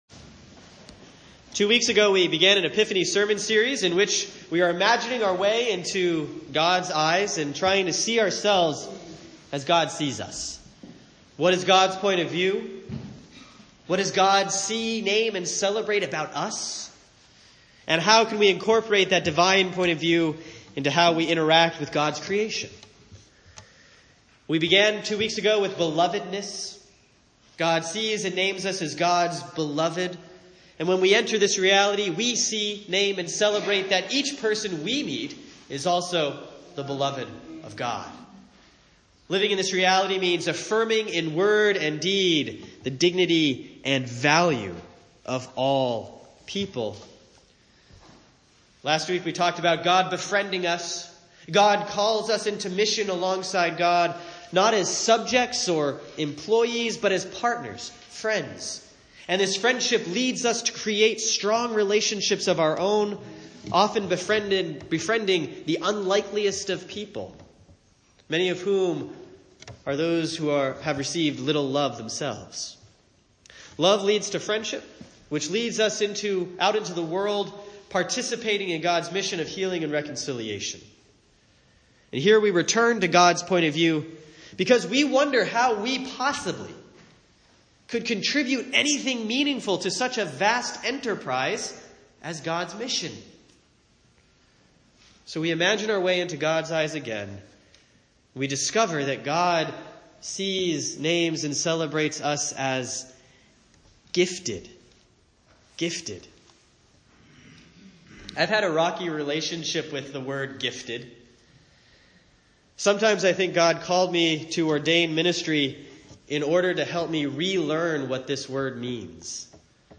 Week three of the Epiphany sermon series on what God sees, names, and celebrates about us. This week: God names us Gifted.